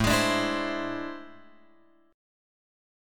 AmM11 chord {5 3 6 4 3 x} chord